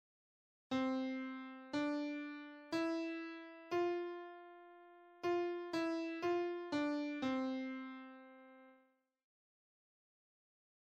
Here’s one example which I made for myself (an intro from a short song I wrote):
C – D – E – F(short pause) – F – E – F – D – C.
buildings_chords_melody.mp3